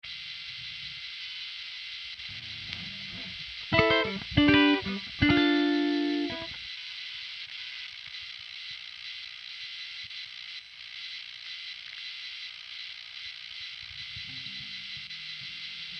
Цифровой шум компьютера при записи гитары
Шум очень такой характерный, и даже покрякивает когда скролю мышкой, на записи слышно. Этот шум происходит с любой гитарой, с любым шнуром, и при любом расстоянии от компа.
Звучит ужасно, записать гитару проблема. Карта fire wire TC Electronic Impact Twin Вложения guit pickup noise.mp3 guit pickup noise.mp3 627,5 KB · Просмотры: 2.836